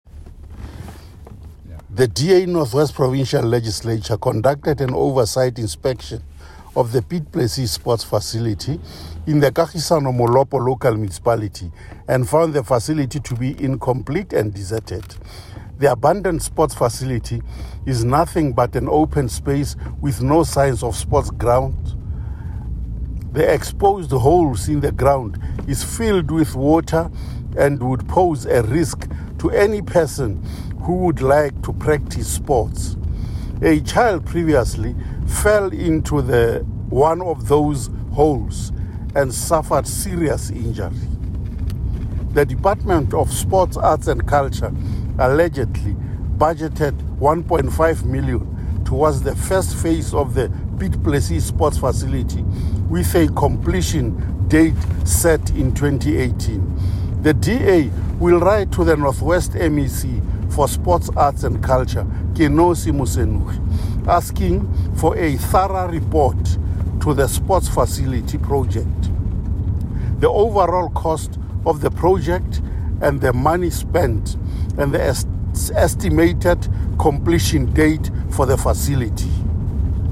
Note to Broadcaster: Please find a linked soundbite in
English by Winston Rabitapi MPL.